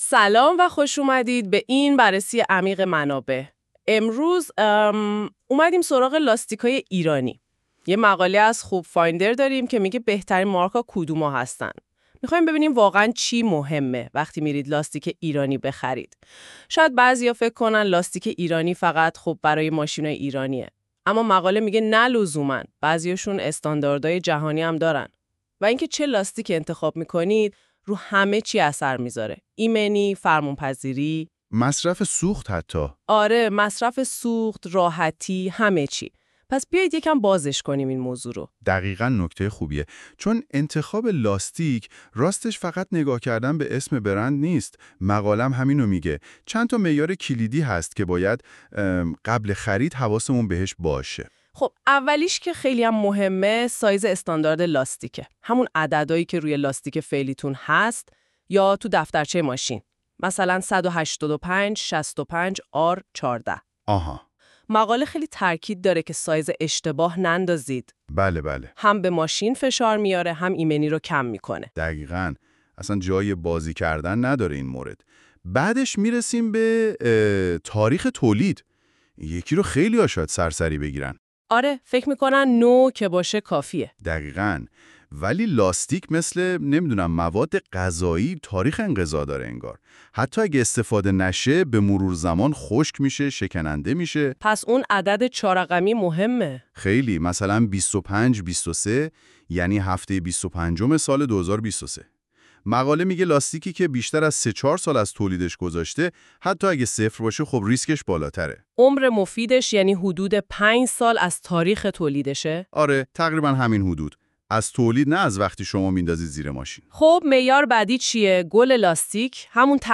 🎧 خلاصه صوتی بهترین مارک لاستیک ایرانی
این خلاصه صوتی به صورت پادکست و توسط هوش مصنوعی تولید شده است.